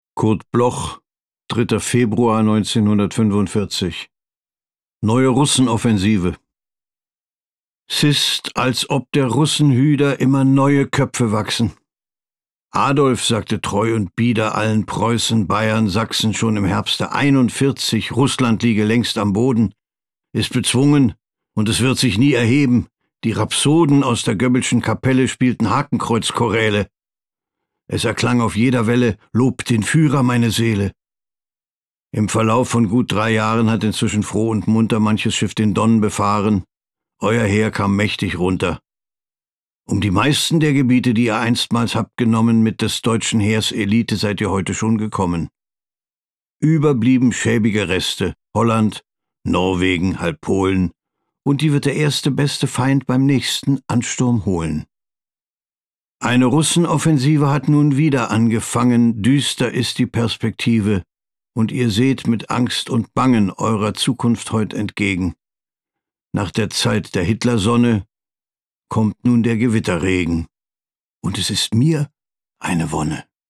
Burghart Klaußner (* 1949) is a German actor, director, voice actor, singer and author.
Recording: speak low, Berlin · Editing: Kristen & Schmidt, Wiesbaden